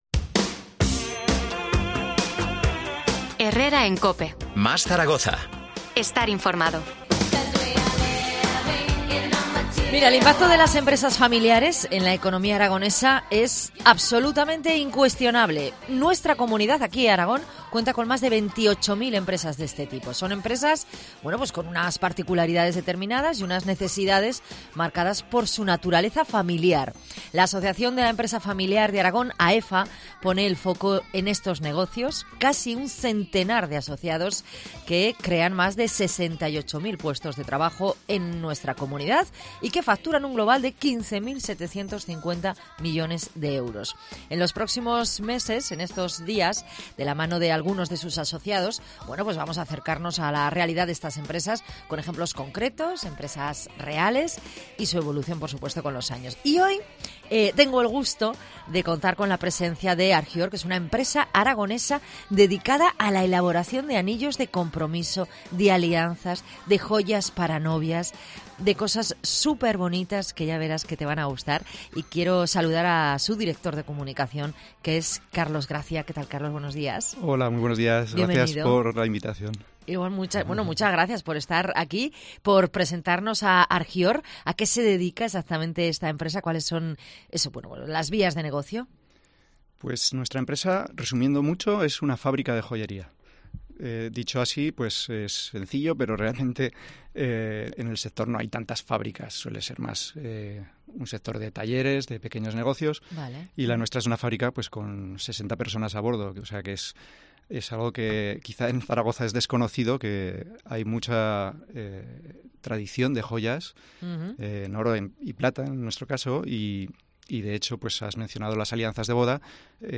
Entrevista AEFA